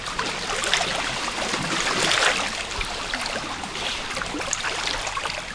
1 channel
waves1.mp3